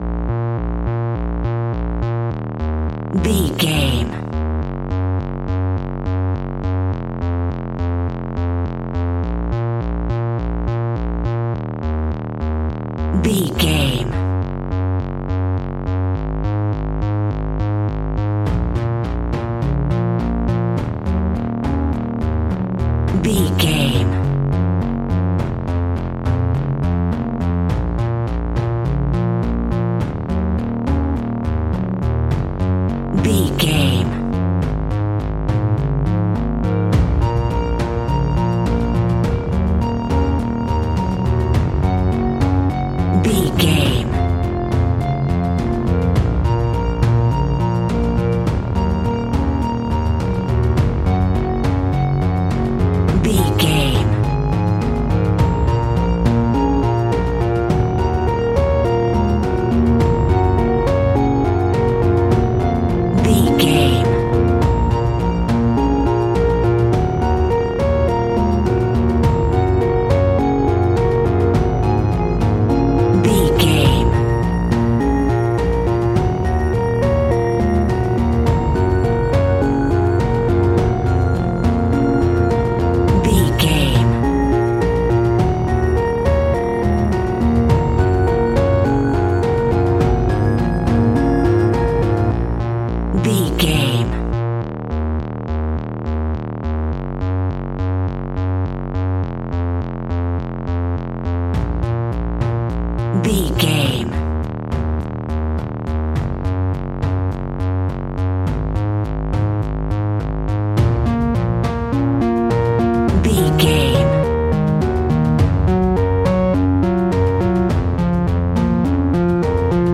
Aeolian/Minor
B♭
suspense
dark
piano
synthesiser